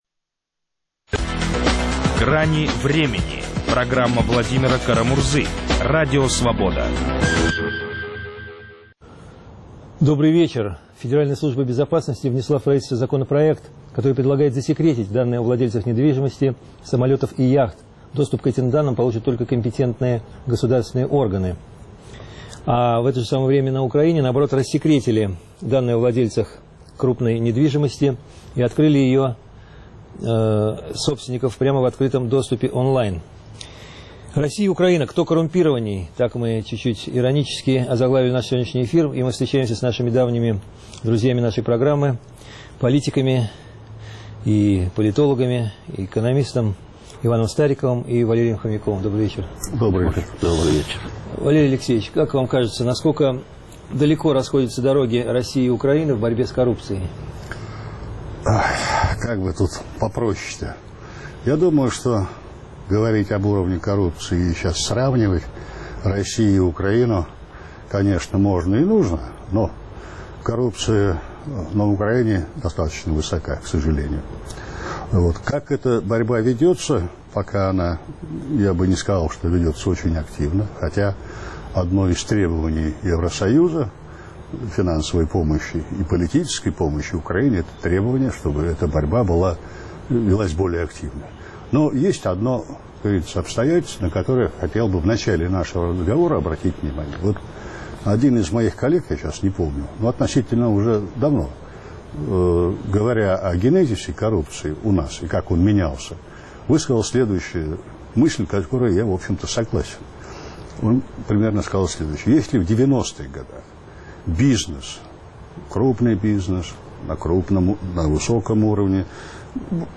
В России ФСБ предлагает засекретить данные о виллах и яхтах. В Украине данные о владельцах земли открыли on-line. Об экономической политике Москвы и Киева, реформах и коррупции спорят экономисты Владимир Лановой (Украина), Иван Стариков